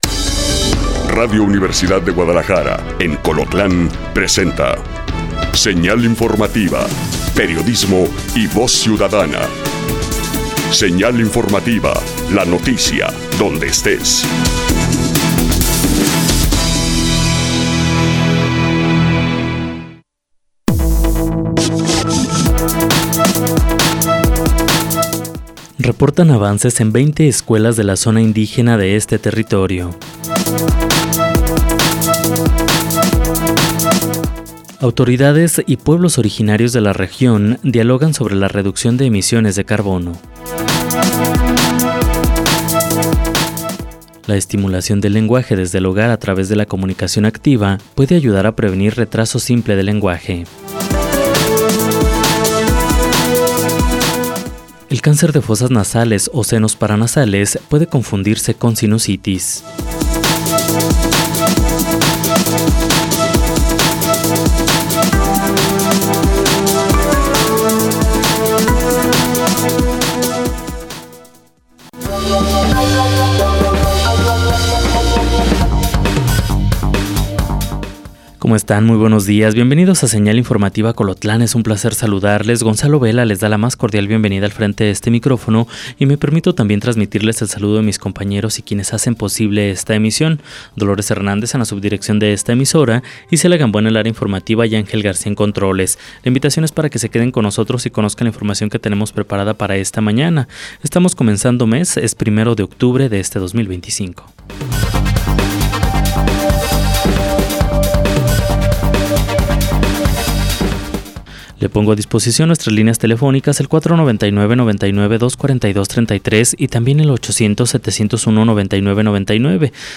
En este noticiero, nos enfocamos en las noticias locales que afectan directamente su vida y su entorno. Desde políticas y eventos comunitarios hasta noticias de última hora y reportajes especiales.